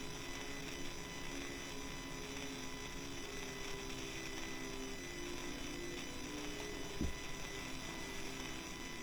Help with a whine.
Nope, this is a desktop and the whine is more like a buzz saw or table saw.
Ok, took some fiddling, and digging out my lapel mic so I could record straight from the headphones, but here we go.
The actual high-pitched screeching is USB error, or the data stream leaking into the battery cables.